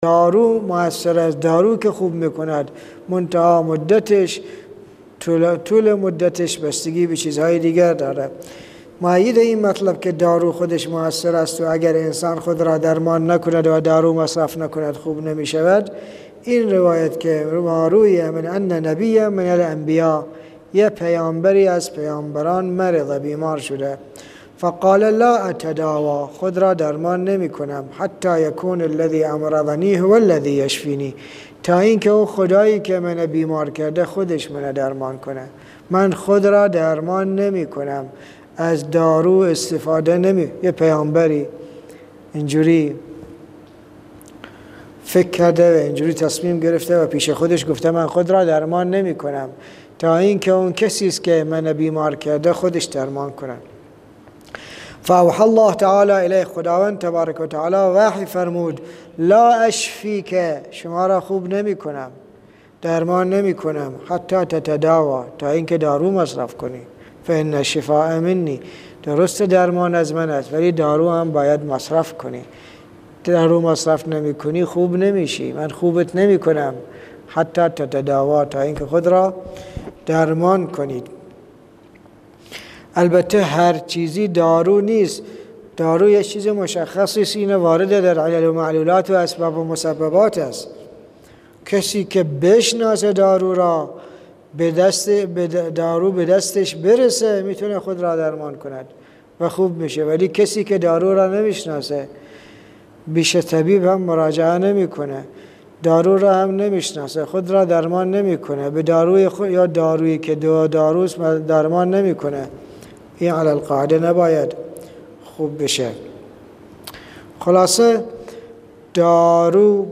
صوت تدریس جلد 2 ، جلسه 5